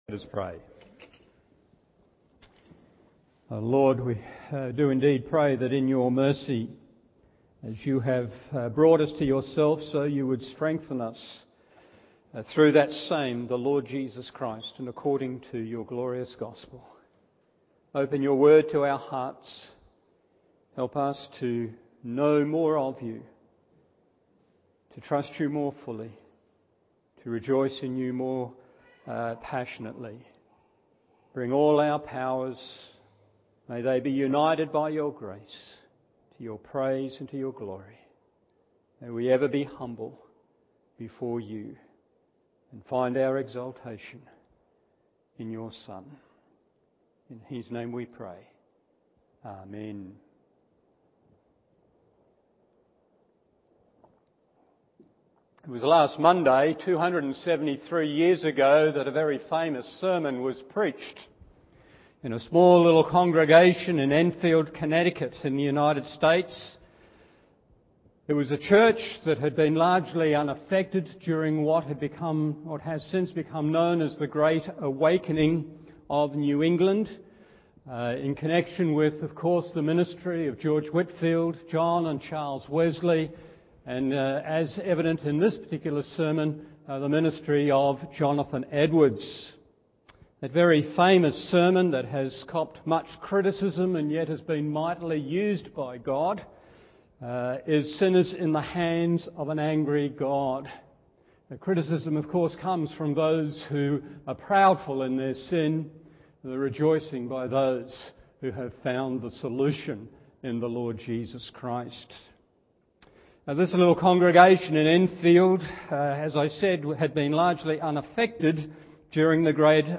Morning Service Genesis 43 1.